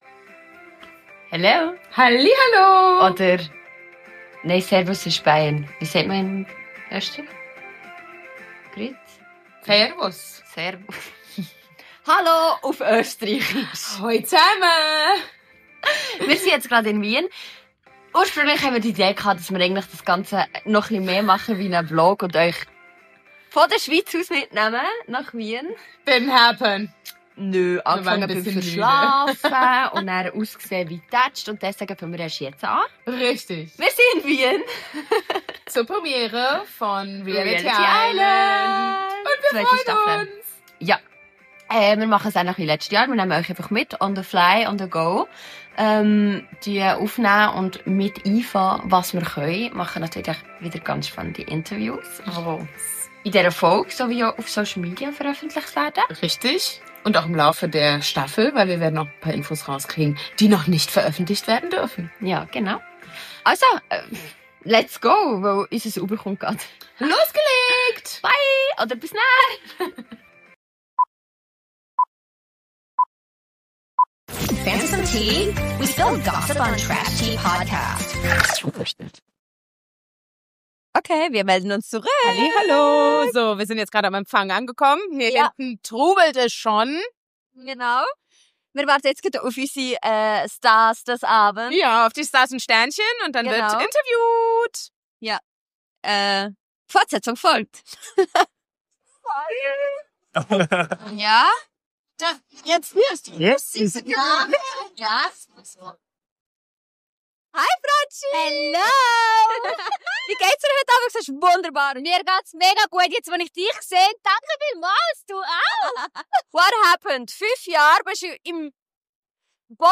Wir waren für euch (und für uns hihi) wieder an der Premiere der 3. Staffel von reality Island / Match in Paradise und haben euch den Abend hindurch mitgenommen. Freut euch auf spannende Interviews und einen Blick hinter die Kulissen!